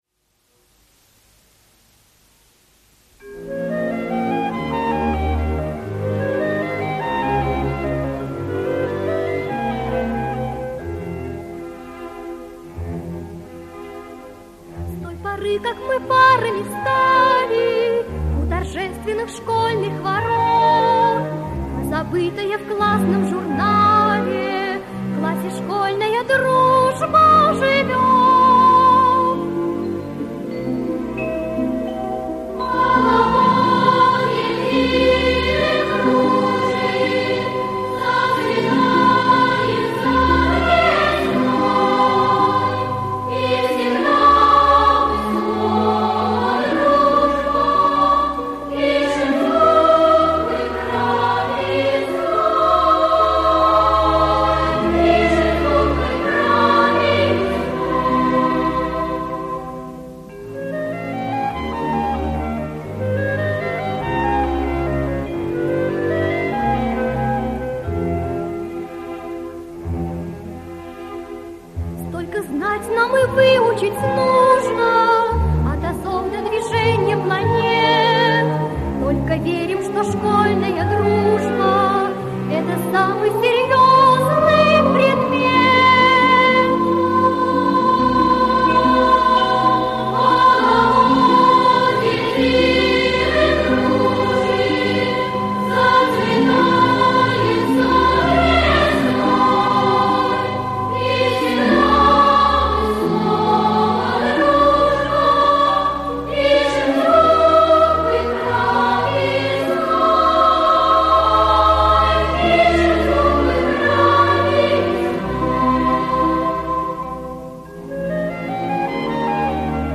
солистка